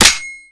sg552_bolt.wav